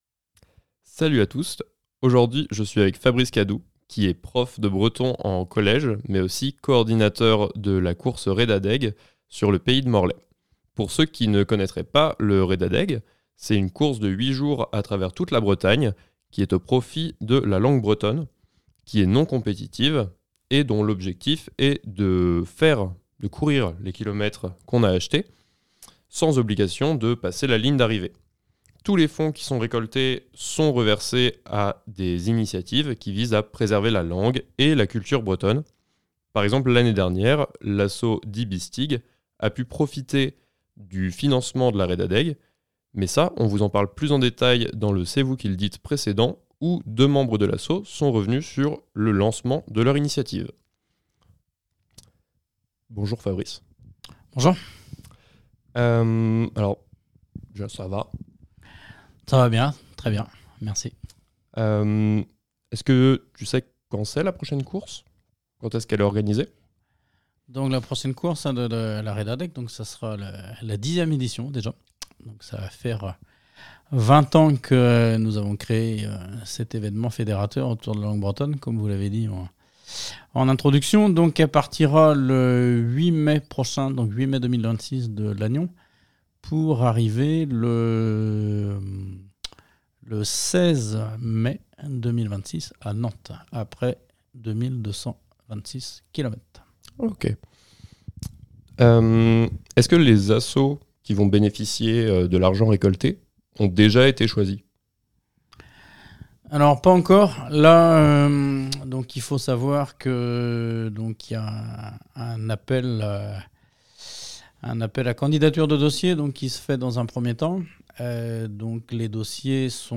Discussion autour de l’importance de la langue bretonne